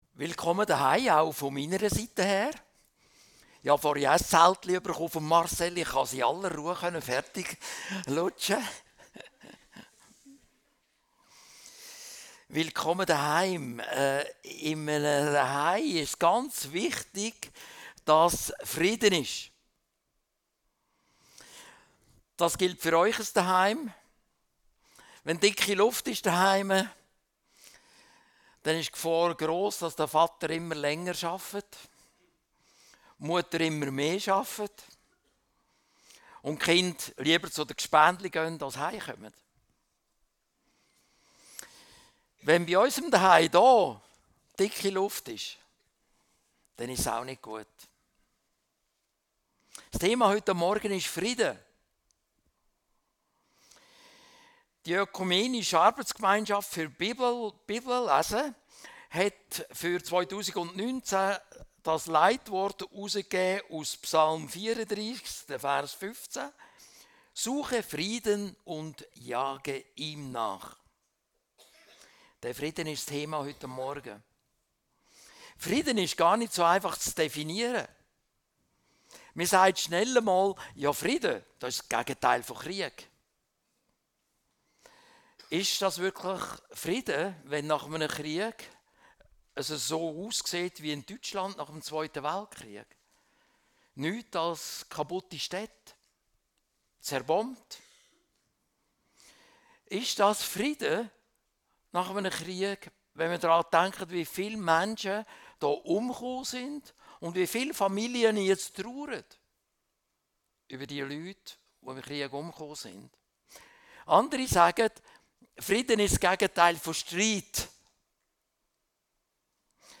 Suche Frieden - seetal chile Predigten